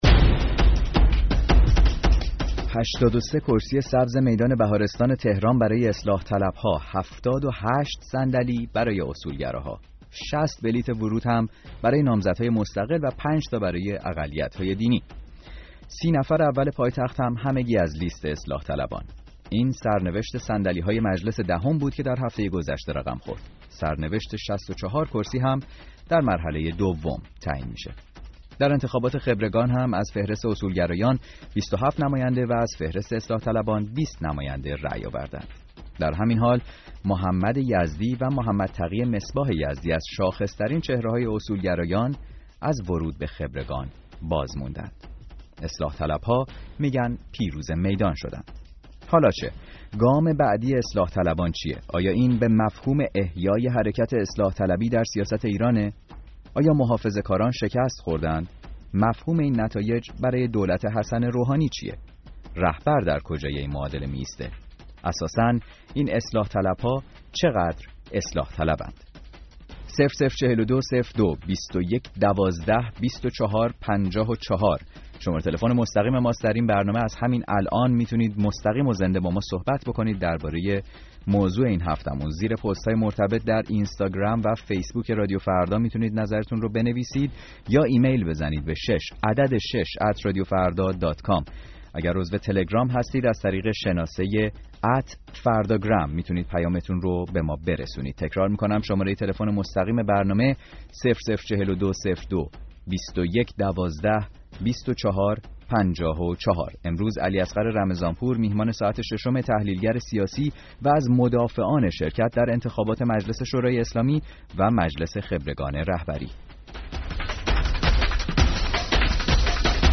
برنامه ساعت ششم این هفته میزبان مخاطبان رادیو فردا